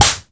punch_slap_whack_hit_01.wav